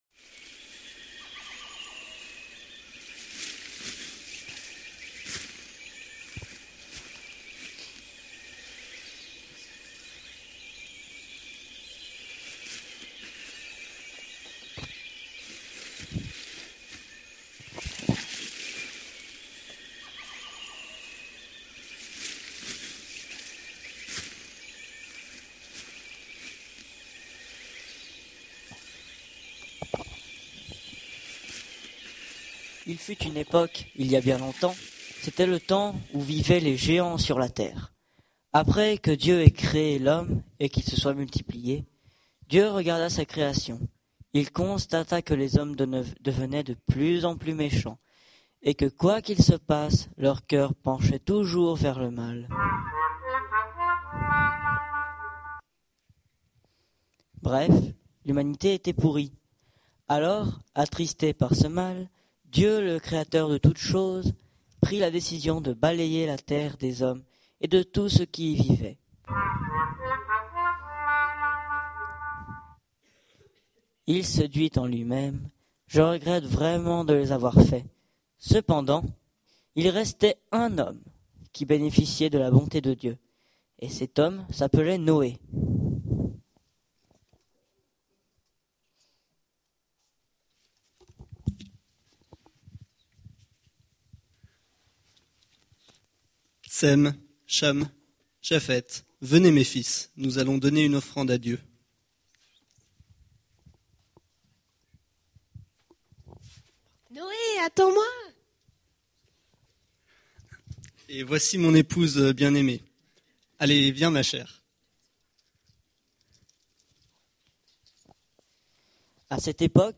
Culte du 23 juin